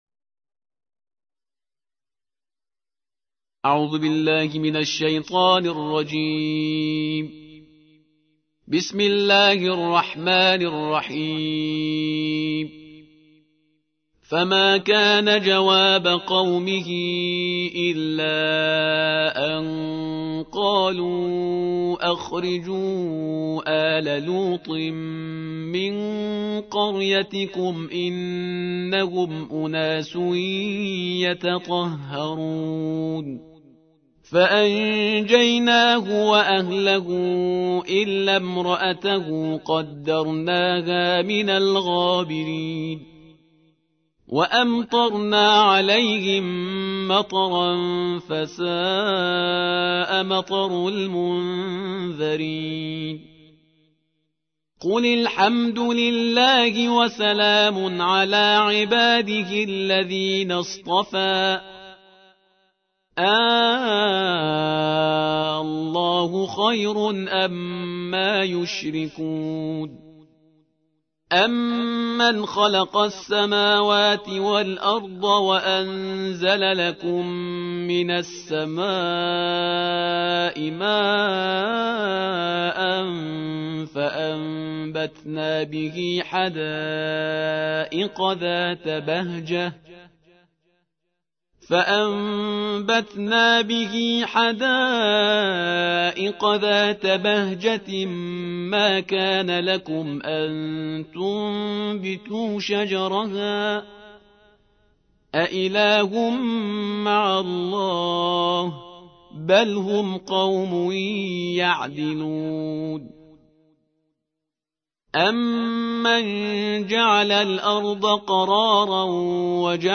الجزء العشرون / القارئ